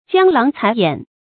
江郎才掩 注音： ㄐㄧㄤ ㄌㄤˊ ㄘㄞˊ ㄧㄢˇ 讀音讀法： 意思解釋： 同「江淹才盡」。